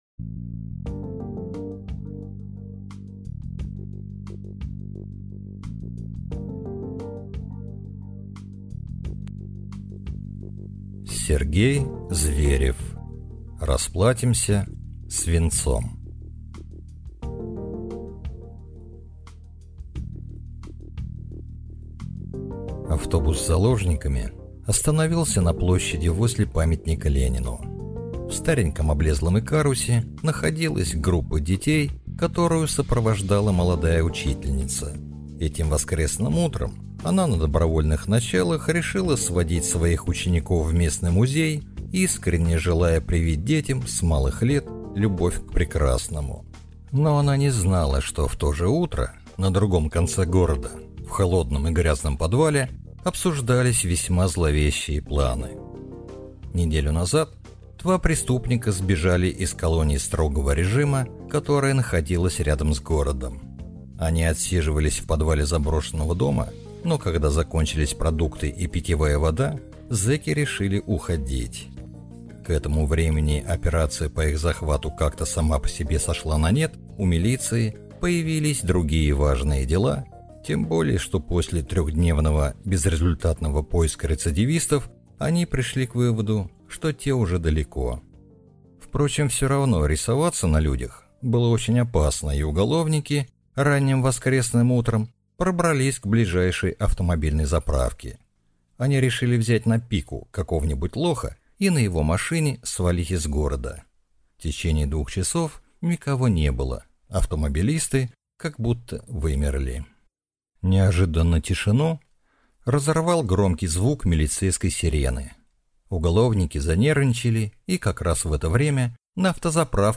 Аудиокнига Расплатимся свинцом | Библиотека аудиокниг